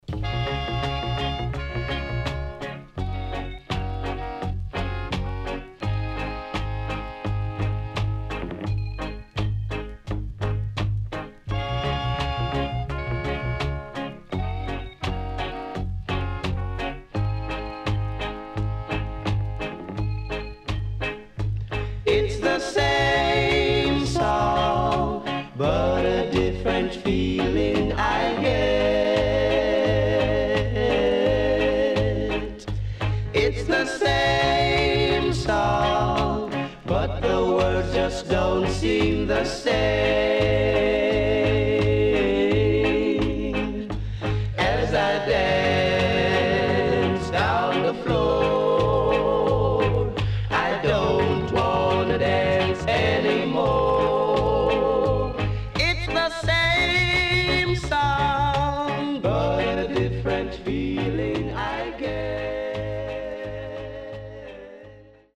Good Horn Inst & Foundation Rocksteady.Good Condition
SIDE A:少しチリノイズ入りますが良好です。